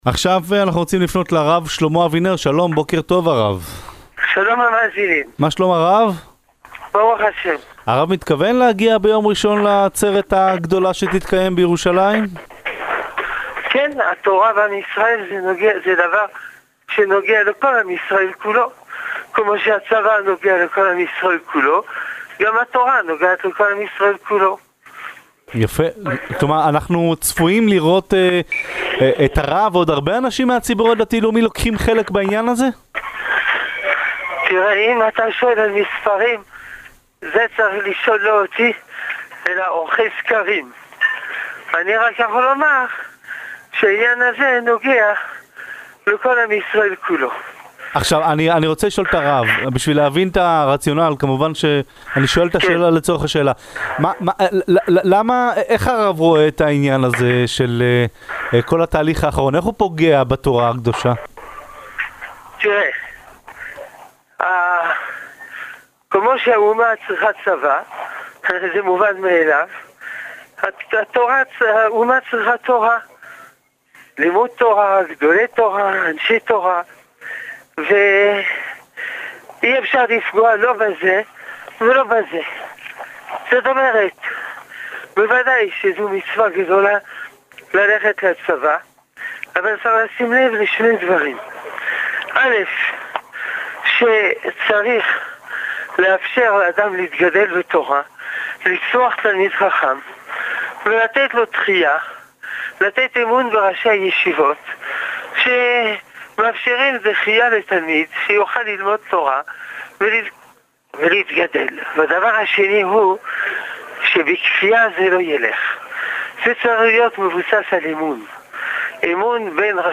בראיון